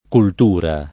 cultura [ kult 2 ra ] s. f.